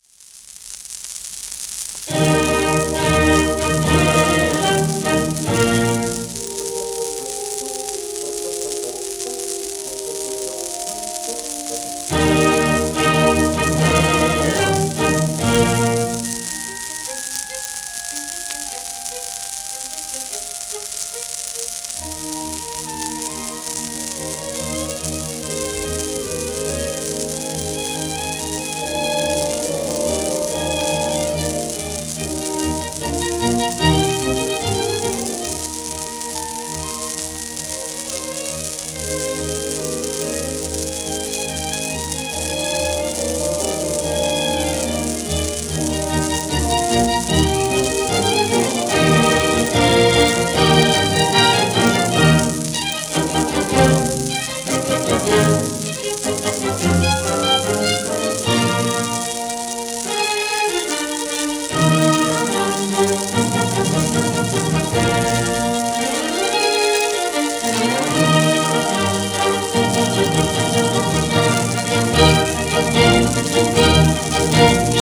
試聴:ピアノ協奏曲第22番変ホ長調 その１